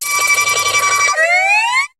Cri de Mélokrik dans Pokémon HOME.